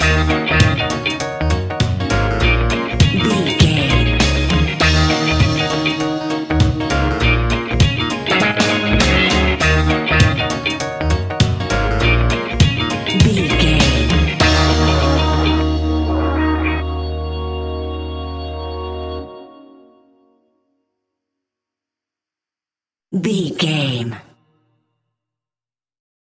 Aeolian/Minor
B♭
laid back
chilled
off beat
drums
skank guitar
hammond organ
transistor guitar
percussion
horns